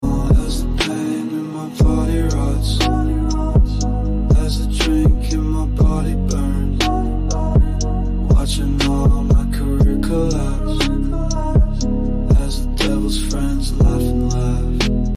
The Devil's Laughter A Dark Sound Effects Free Download